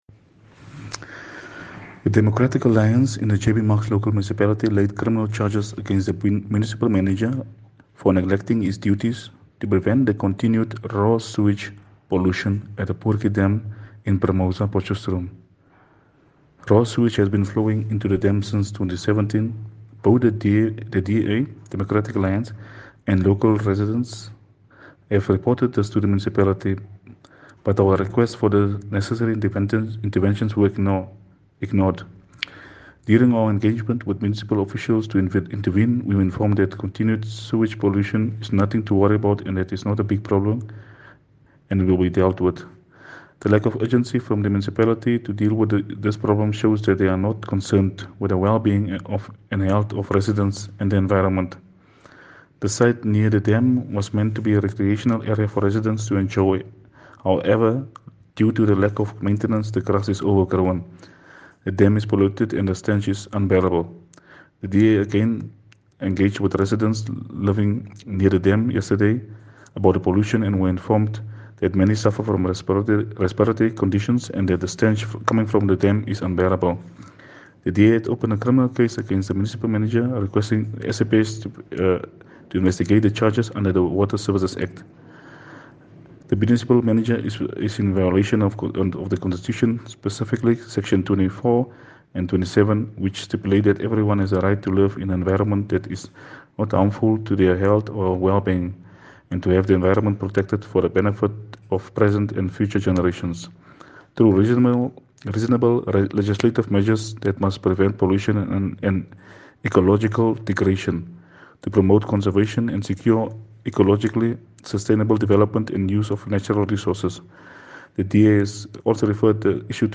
soundbite from Councillor Glenville Fransman.